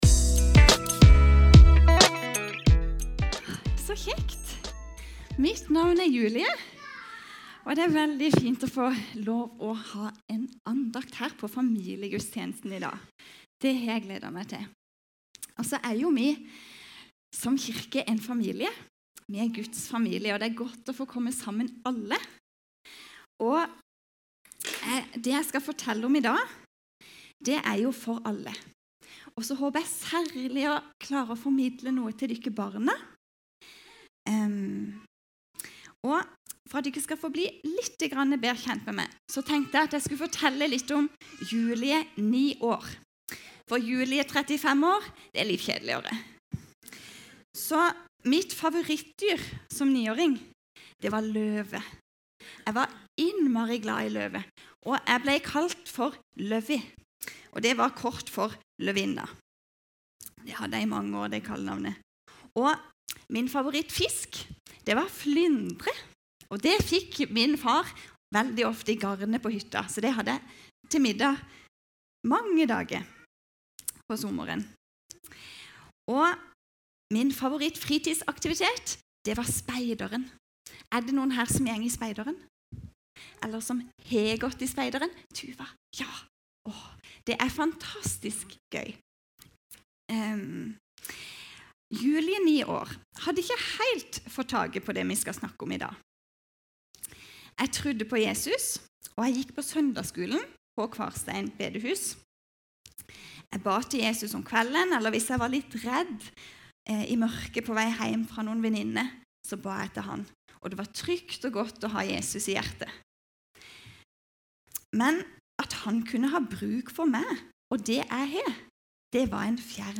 Taler fra Norkirken Vennesla